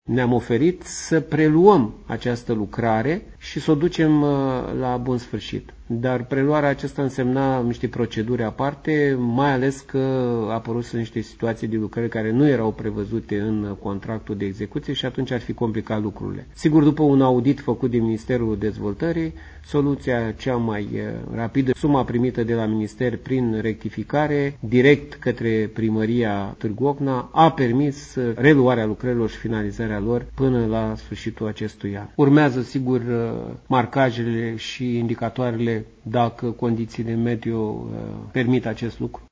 Preşedintele Consiliului Judeţean Bacău, Sorin Braşoveanu a declarat, pentru Radio Iaşi, că a vrut să preia lucrarea însă până la urmă guvernul a trimis din nou fonduri pentru acest obiectiv: